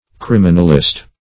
Criminalist \Crim"i*nal*ist\, n. One versed in criminal law.
criminalist.mp3